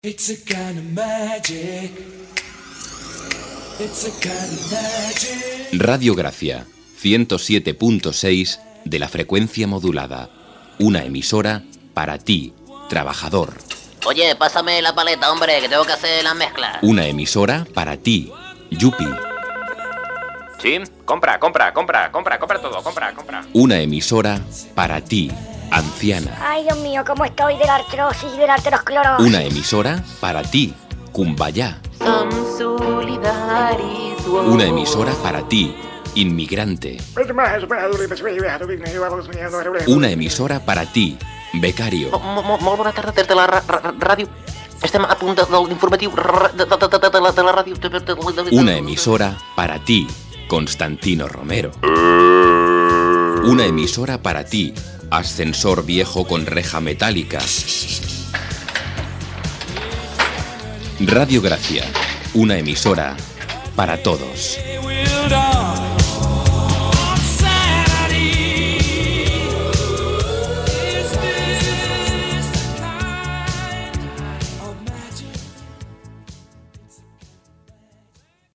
Indicatiu de l'emissora "Ràdio Gràcia una emisora para todos".